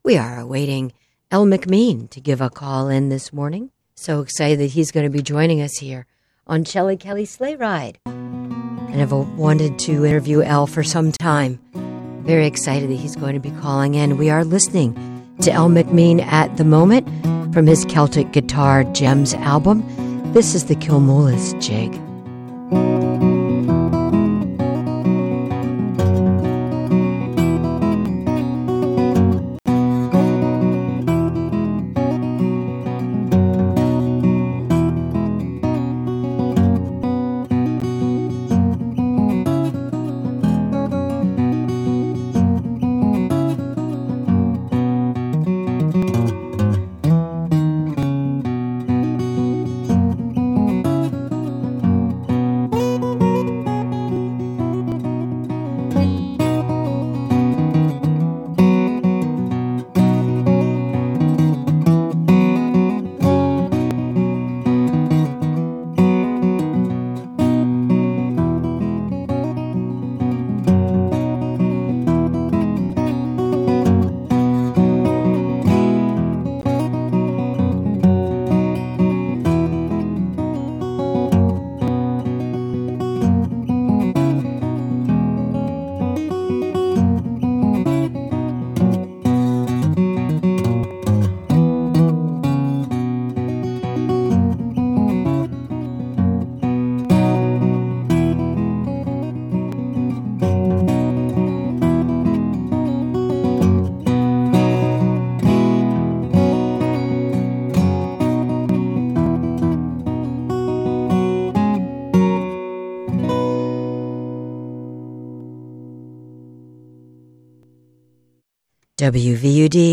interview
on WVUD radio in Newark, Delaware